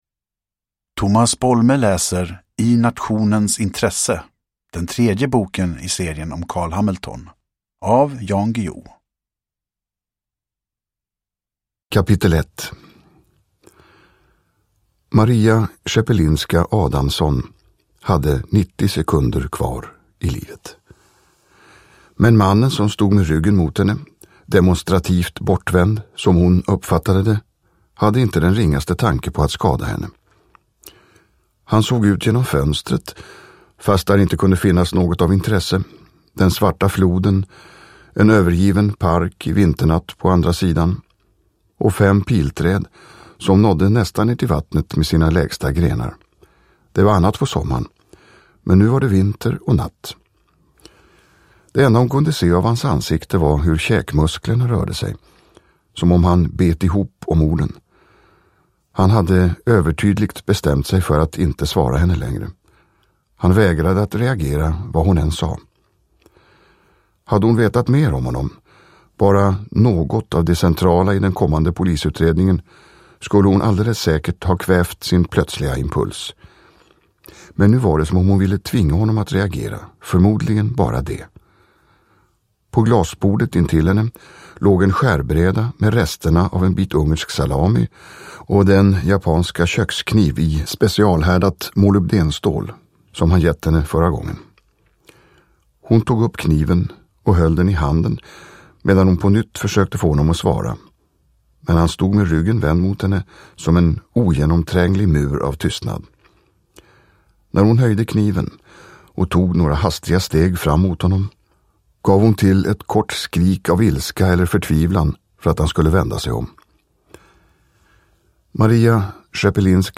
I nationens intresse – Ljudbok
Uppläsare: Tomas Bolme